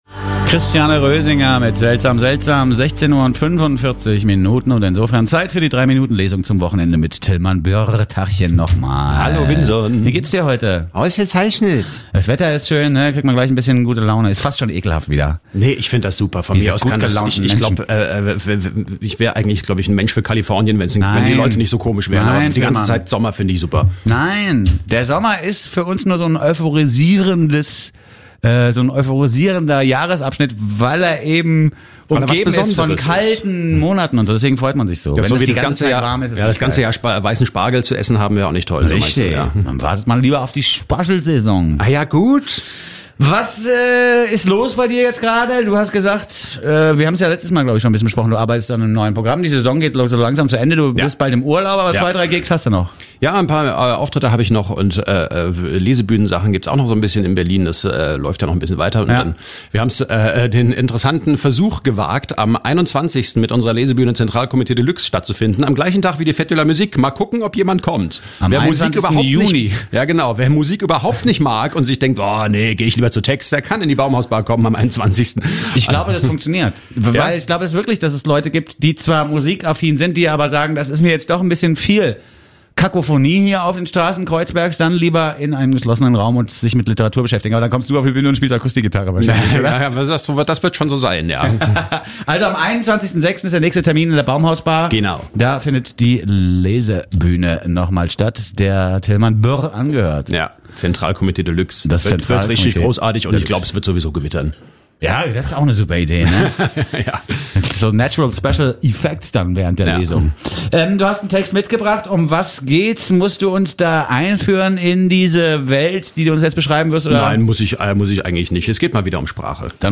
3-Minuten-Lesung zum Wochenende
Das Interview